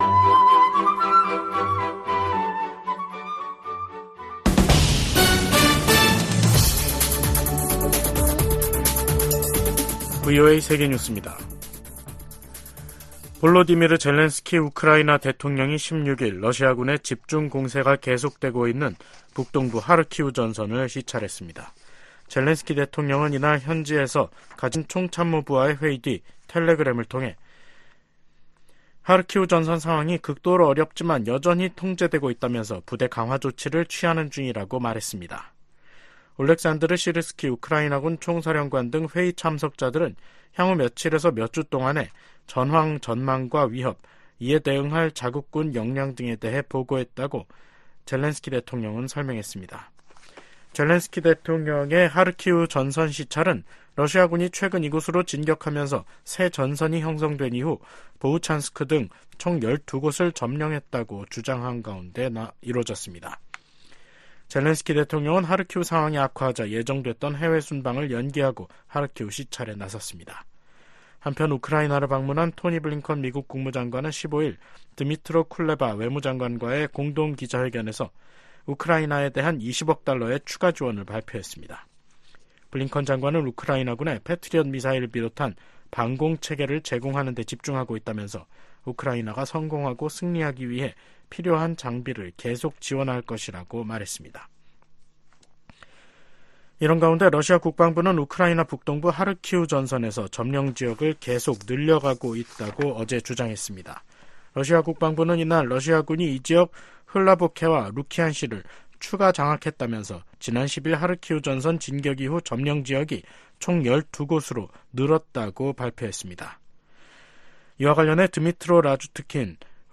세계 뉴스와 함께 미국의 모든 것을 소개하는 '생방송 여기는 워싱턴입니다', 2024년 5월 16일 저녁 방송입니다. '지구촌 오늘'에서는 블라디미르 푸틴 러시아 대통령과 시진핑 중국 국가주석이 베이징에서 정상회담을 하고 양국 간 포괄적인 전략적 협력 관계를 심화하기로 선언한 소식 전해드리고, '아메리카 나우'에서는 조 바이든 대통령과 도널드 트럼프 전 대통령이 오는 6월과 9월 두 차례 TV 토론을 갖기로 합의한 이야기 살펴보겠습니다.